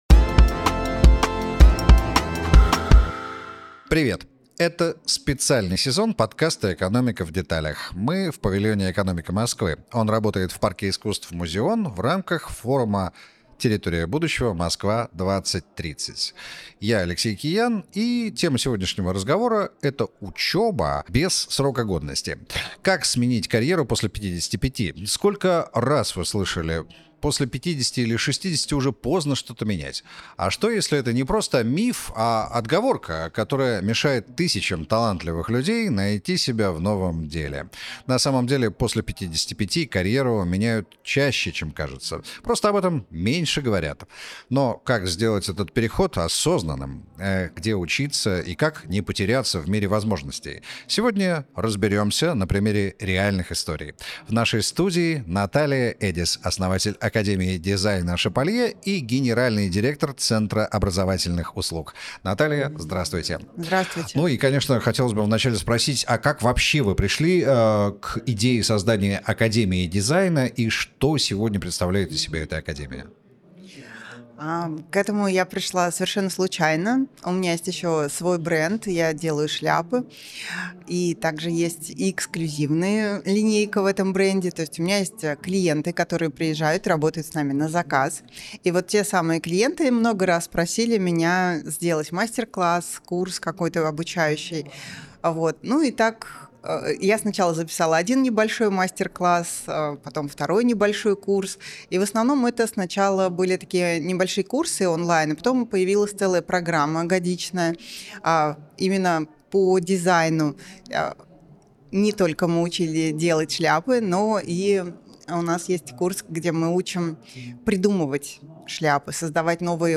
В этом выпуске разбираем, может ли творчество во взрослом возрасте стать новой профессией и источником дохода. Эксперт объясняет, почему онлайн-формат идеально подходит для освоения ремесла и почему 90-летние студенты — уже не редкость. Подкаст «Экономика в деталях» — цикл бесед об устройстве городской экономики и о грамотном подходе к жизни и самореализации в мегаполисе.